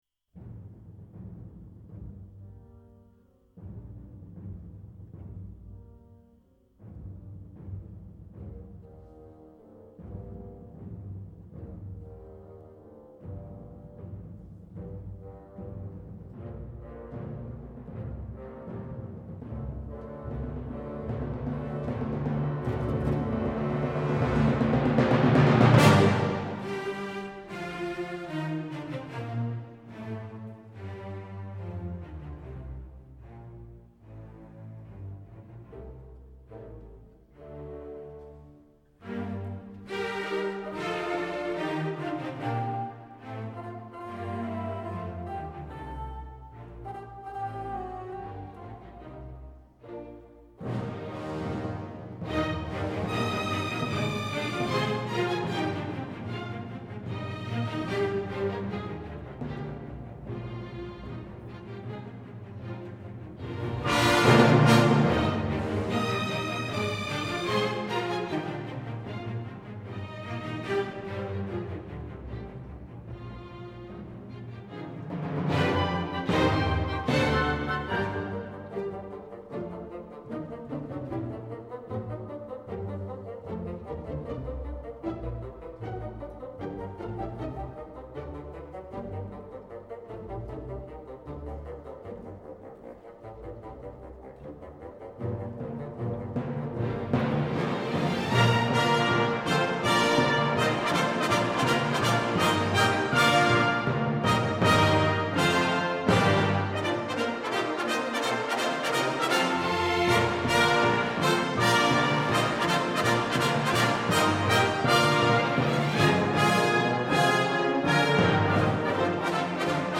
This is a fun piece for brass, and I marveled at the way the composer was able to represent the guillotine blade falling and the head tumbling down the steps.
Be sure to listen toward the end for the solo clarinet followed by the "WOMP!" of the guillotine blade (followed by the "bump... bump... bump..." of the severed head tumbling down the steps).
For those interested, this recording was performed by Riccardo Muti conducting the Philadelphia Orchestra.